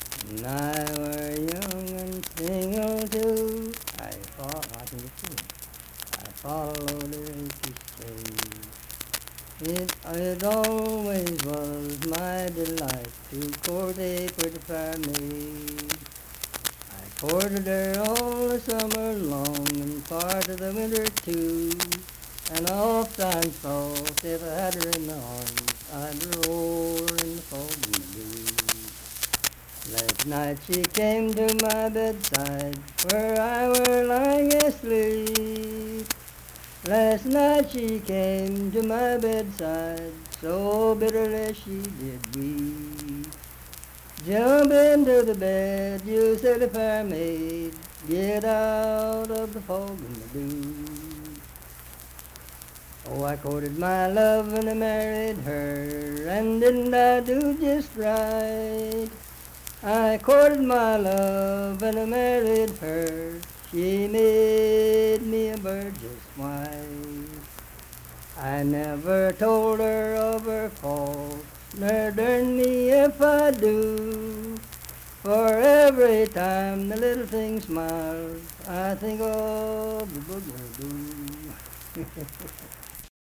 Unaccompanied vocal music
Verse-refrain 6(4).
Voice (sung)
Harts (W. Va.), Lincoln County (W. Va.)